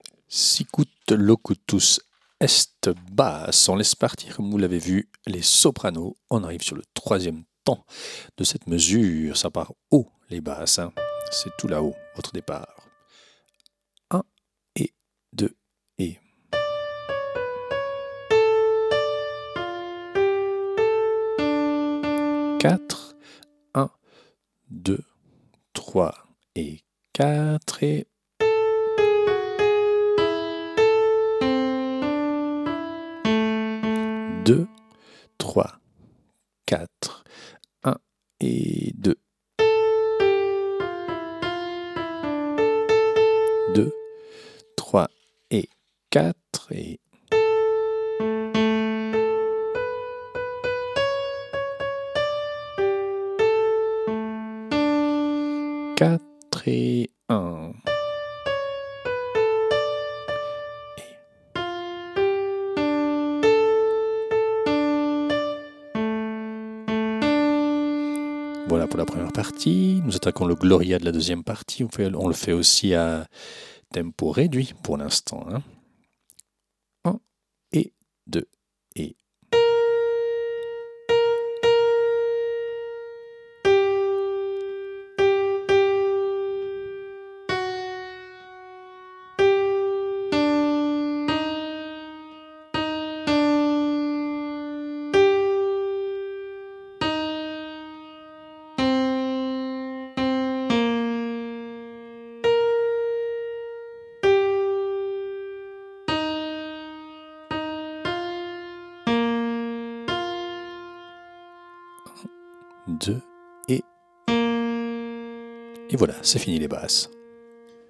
Répétition SATB4 par voix
Basse
Sicut Locutus est Basse.mp3